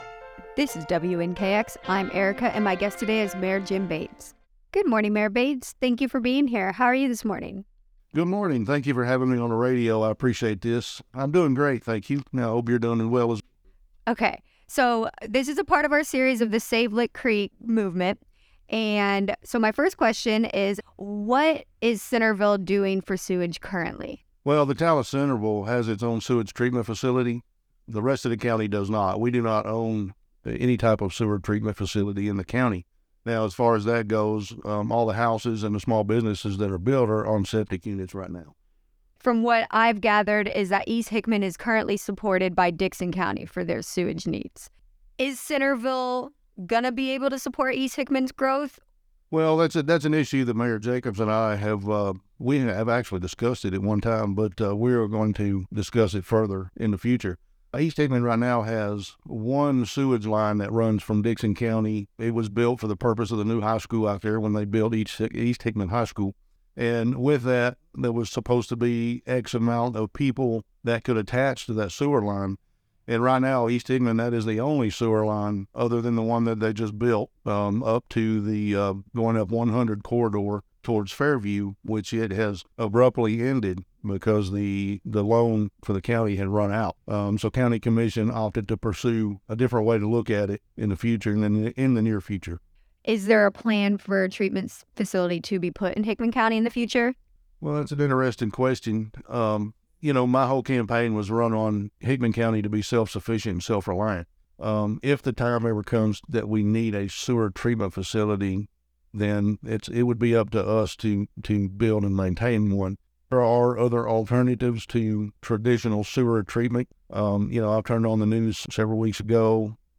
WNKX: Friends of Lick Creek Interview – Mayor Jim Bates (5/12/23)
LickCreek_Interview3-w-soundbed.mp3